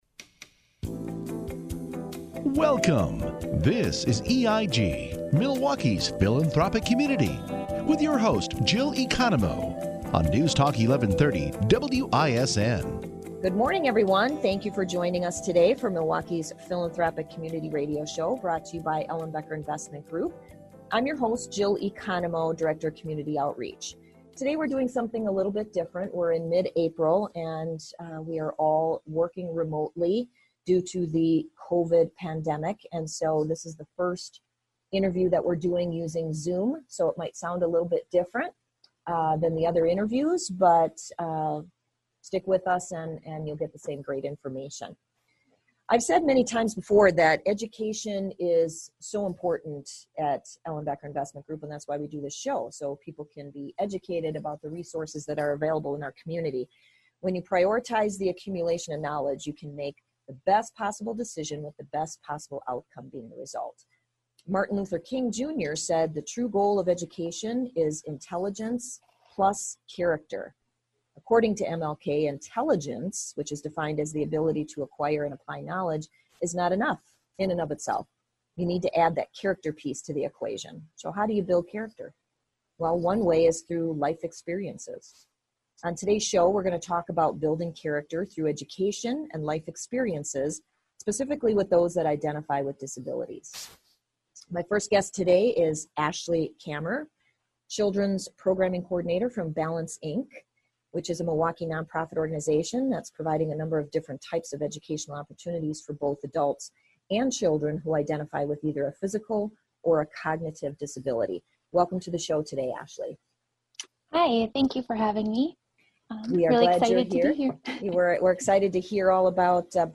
Balance Inc. Originally aired on News Talk 1130 WISN.